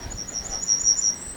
birds.wav